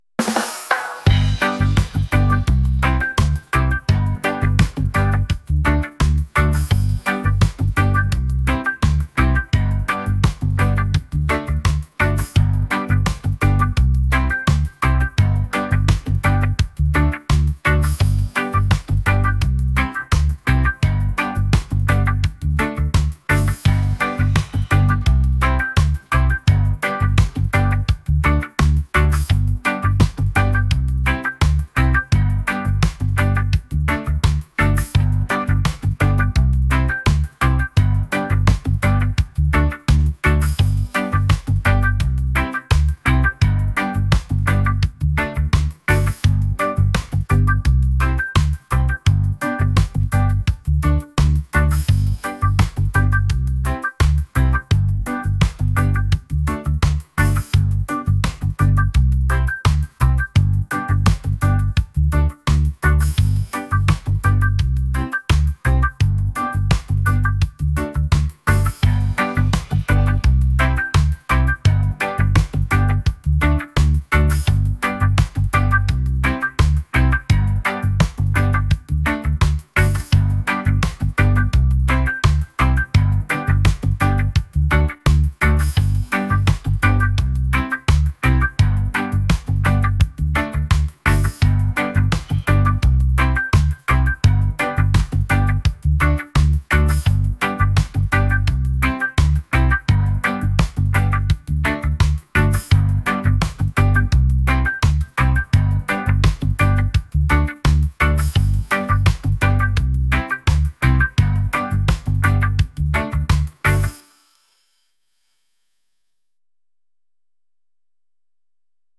rhythmic | reggae | upbeat